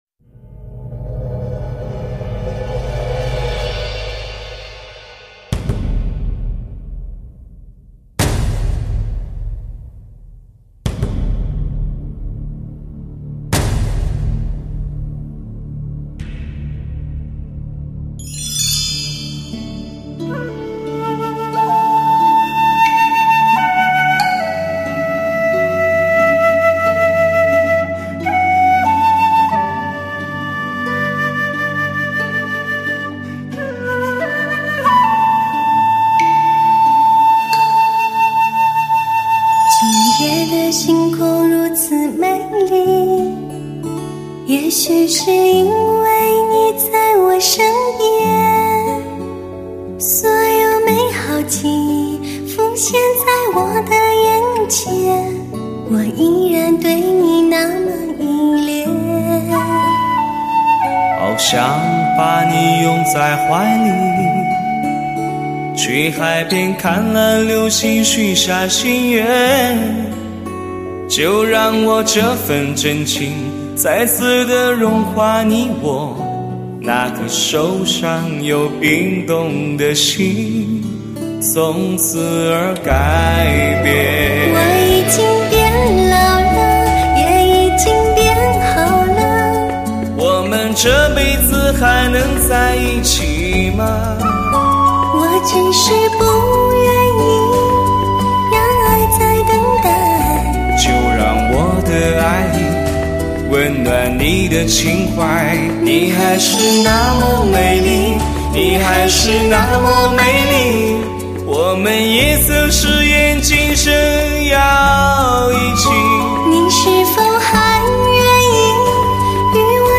1:1直刻德国技术，最完美音质呈现，闷情的冷酒渗着那苦涩的泪，煽情的氛围，思念着远方的你……